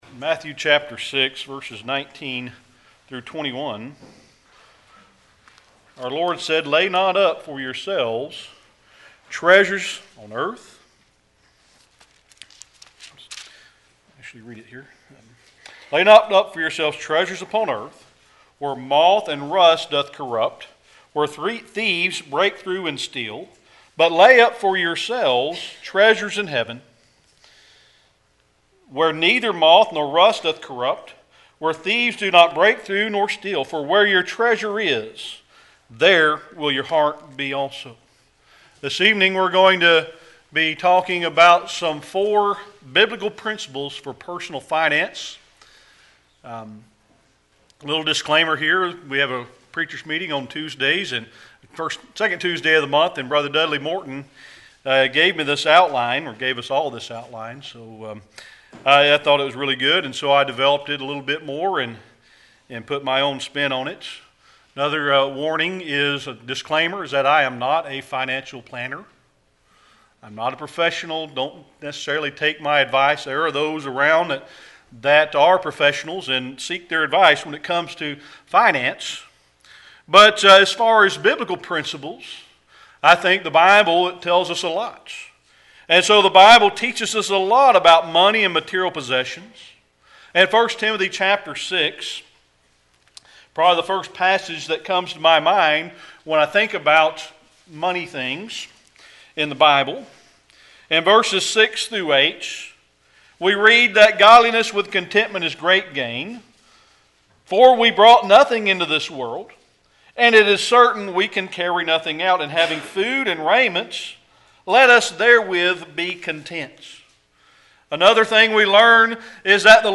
Series: Sermon Archives
Service Type: Sunday Morning Worship